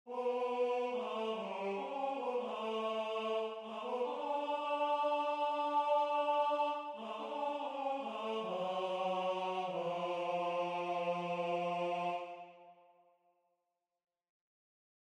Key written in: G Major
Type: Barbershop
Each recording below is single part only.